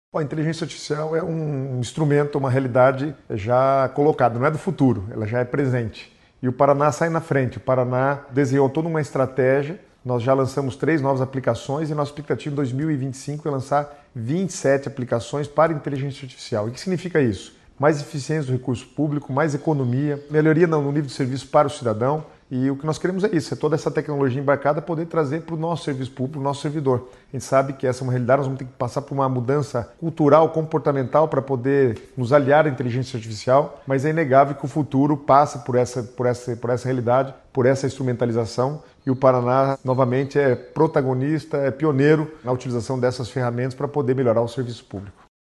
Sonora do secretário do Planejamento, Guto Silva, sobre o investimento do Estado em inteligência artificial